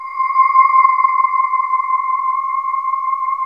Index of /90_sSampleCDs/USB Soundscan vol.28 - Choir Acoustic & Synth [AKAI] 1CD/Partition D/05-SPECTRE